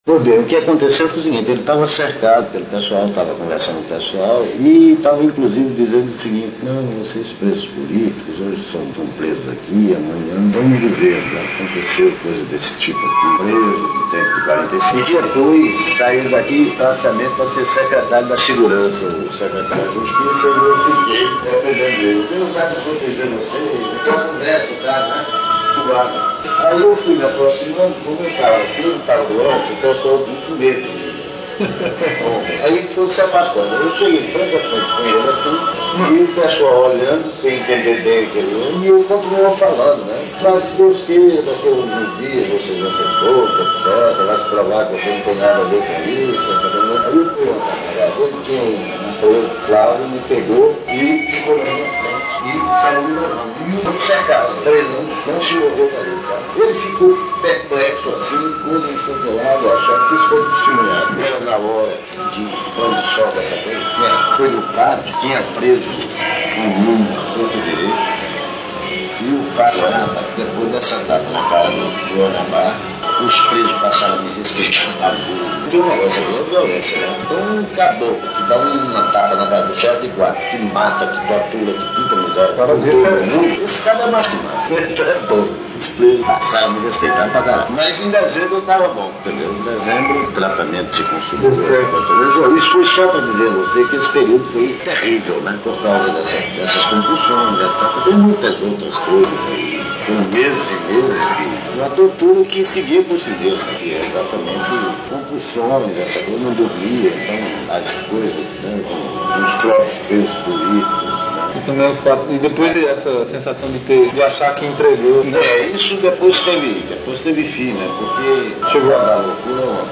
um depoimento.